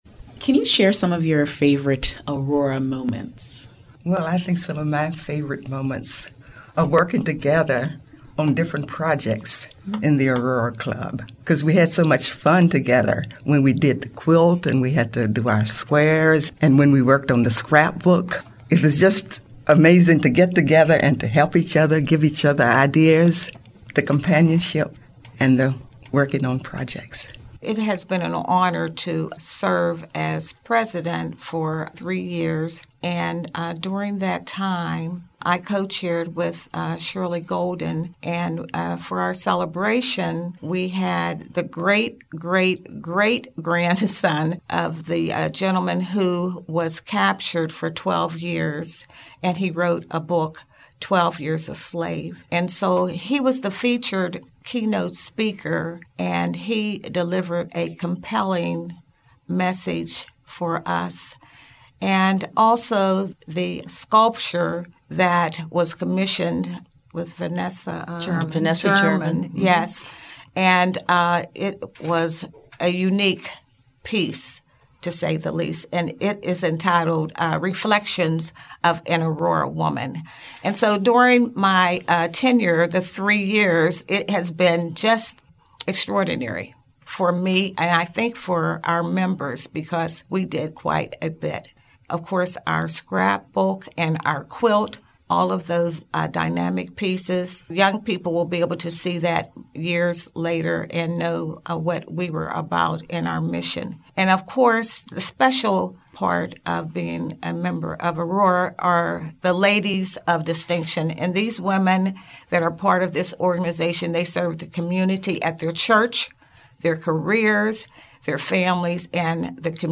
The tracks below were collected from Aurora’s luncheon on April 22nd, 2019.